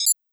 GenericNotification1.wav